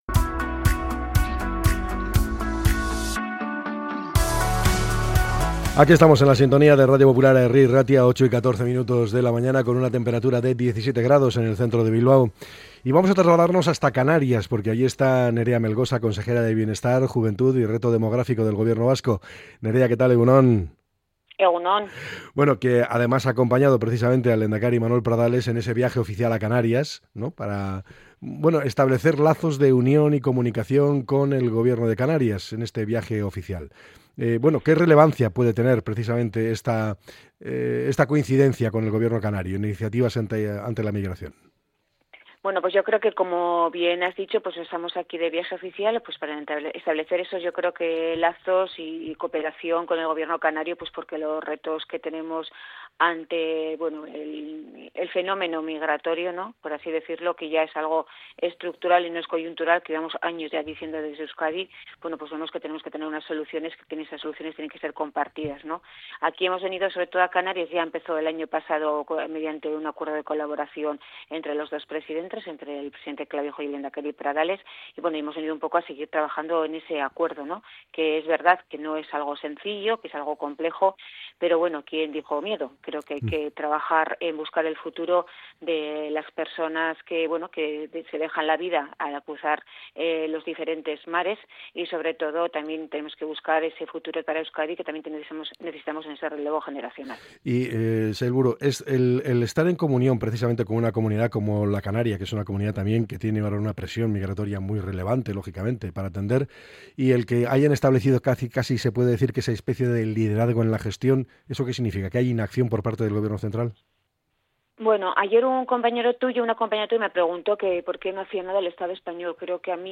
Entrevista con la consejera de Igualdad, Justicia y Políticas Sociales